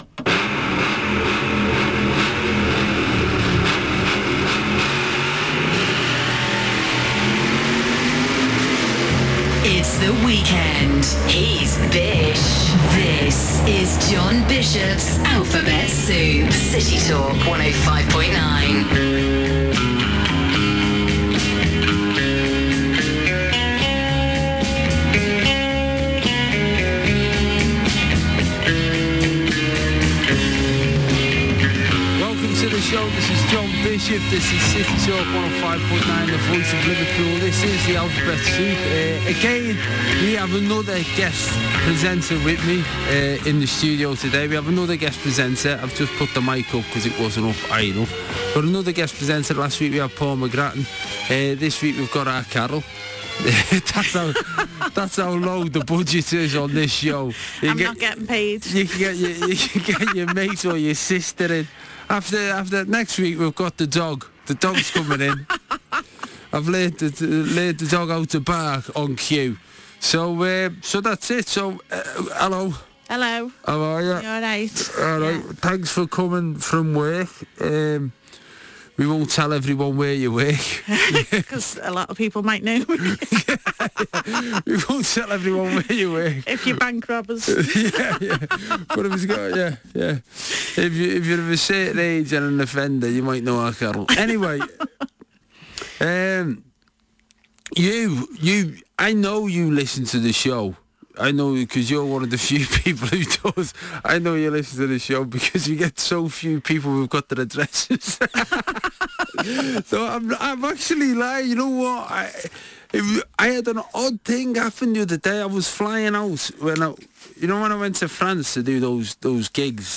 John Bishop has another special guest in the studio